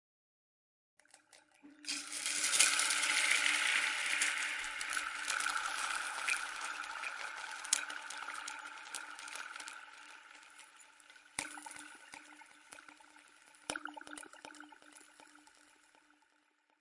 Download Sizzle sound effect for free.
Sizzle